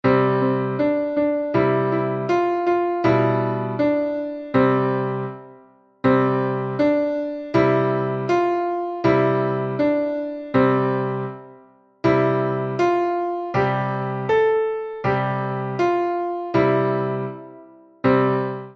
ピアノ伴奏音源